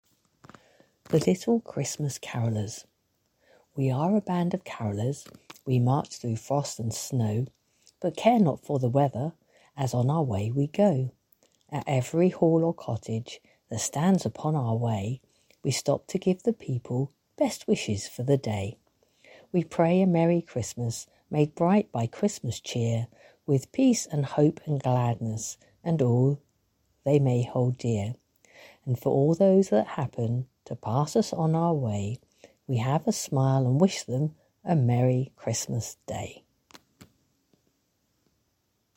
Festive Reading Number 4 | Shoreham Beach Primary School & Nursery